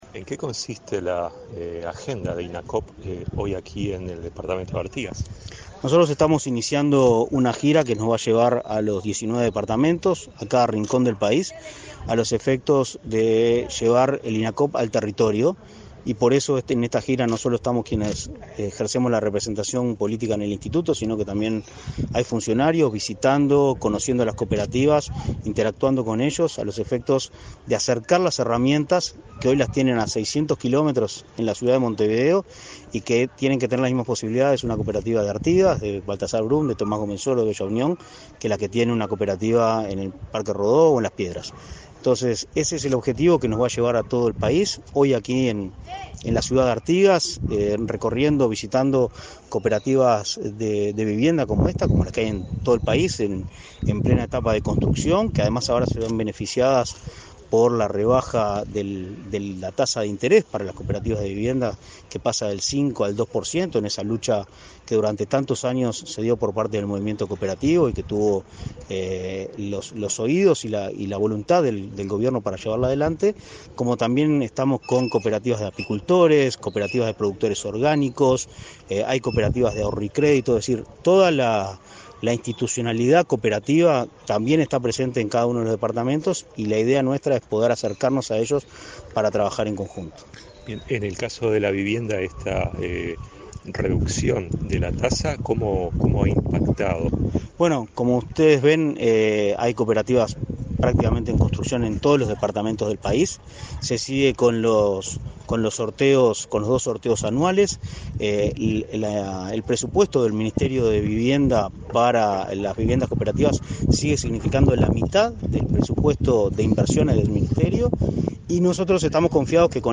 Entrevista al presidente de Inacoop, Martín Fernández
Entrevista al presidente de Inacoop, Martín Fernández 10/05/2023 Compartir Facebook X Copiar enlace WhatsApp LinkedIn El presidente del Instituto Nacional del Cooperativismo (Inacoop), Martín Fernández, recorrió, este 10 de mayo, viviendas de cooperativas en el departamento de Artigas. Tras la actividad, el jerarca realizó declaraciones a Comunicación Presidencial.